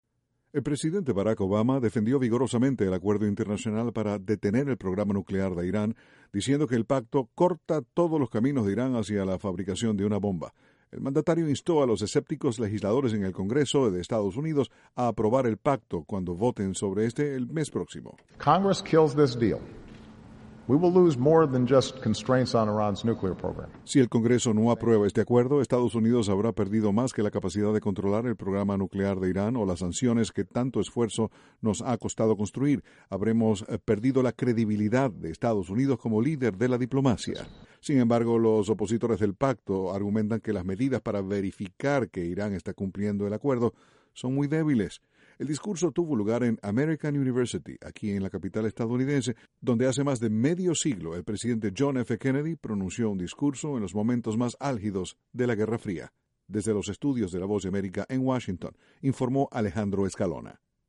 Durante un discurso de casi una hora, el presidente de Estados Unidos Barack Obama instó al Congreso a que apruebe el acuerdo nuclear logrado con Irán. Desde la Voz de América, Washington